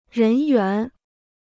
人员 (rén yuán) Free Chinese Dictionary